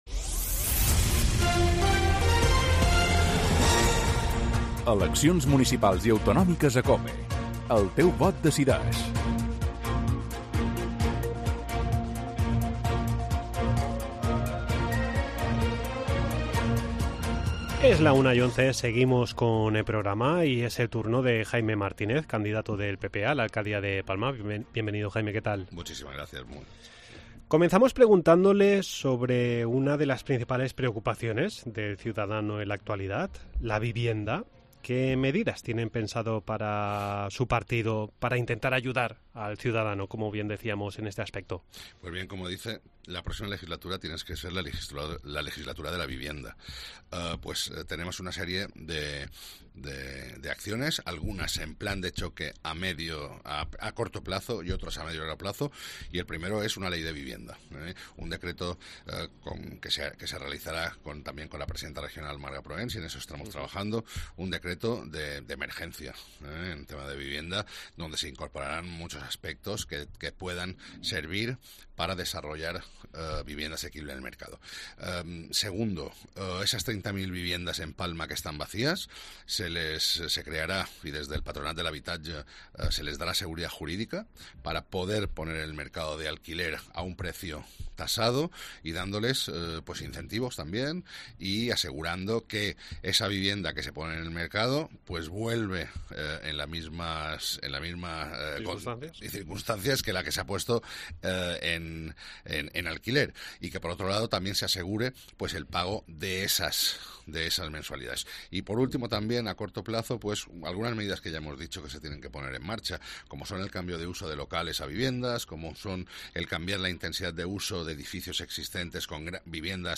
AUDIO: Seguimos con las entrevistas electorales. Hoy es el turno de Jaime Martínez, candidato del Partido Popular a la alcaldía de Palma